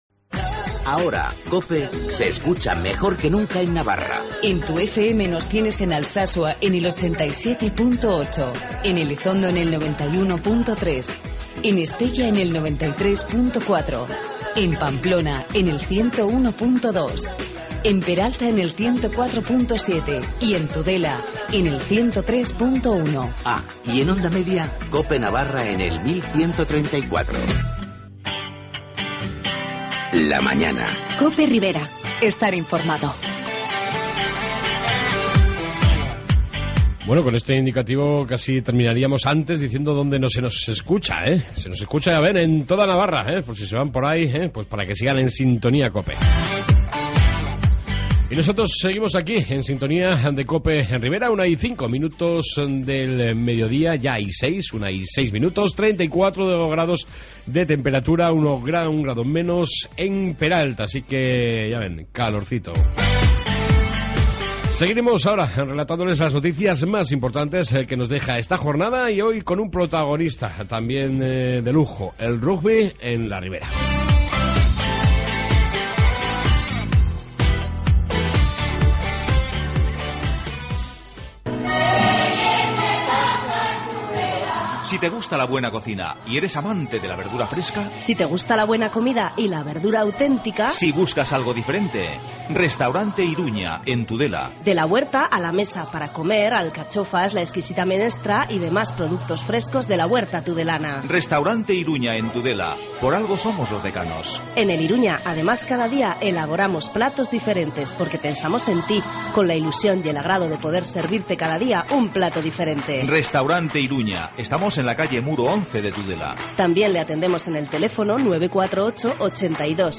AUDIO: En esta 2 parte Entrevista con el gigantes de Navarra de Rugby